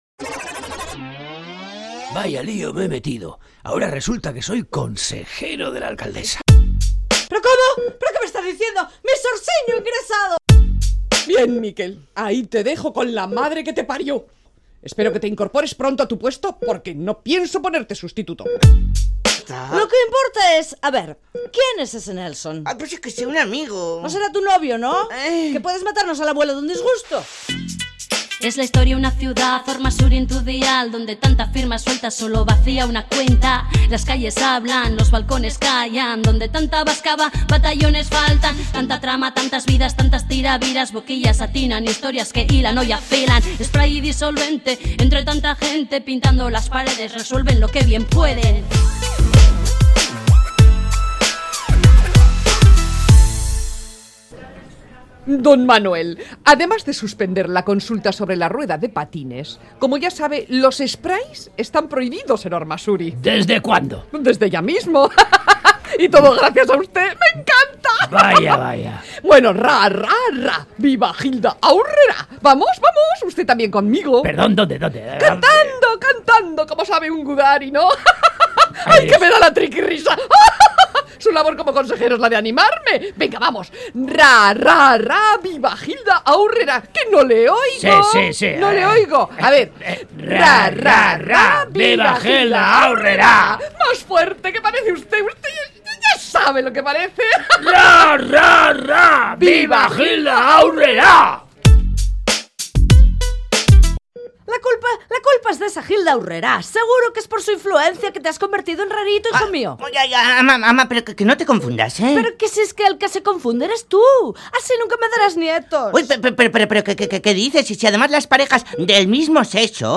Entrega número 15 de la Radio-Ficción “Spray & Disolvente”